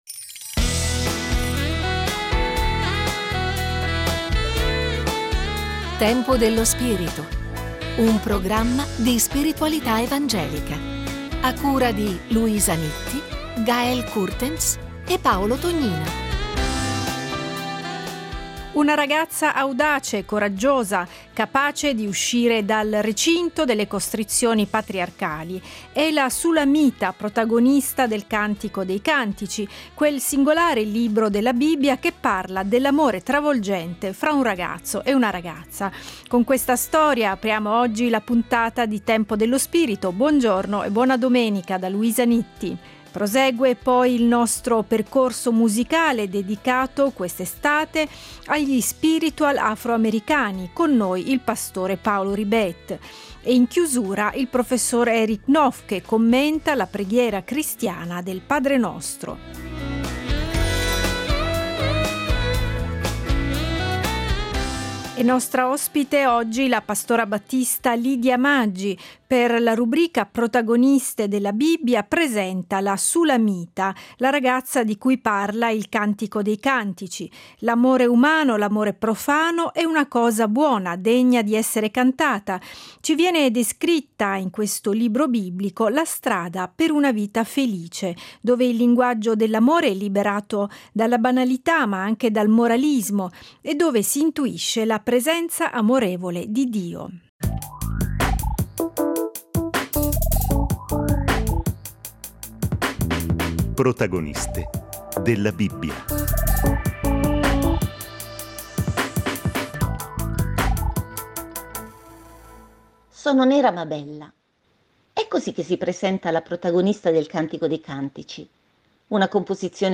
Conversazione evangelica